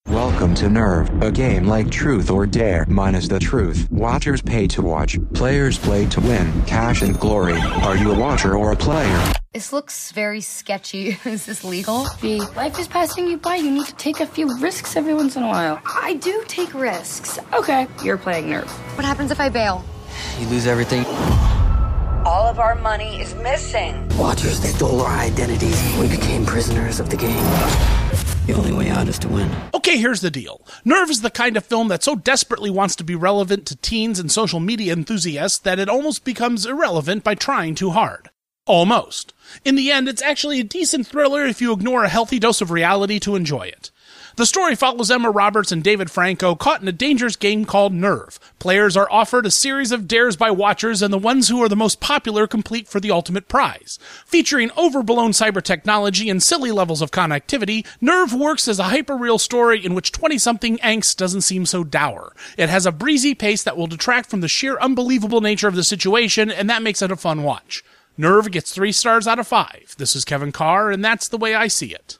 ‘Nerve’ Radio Review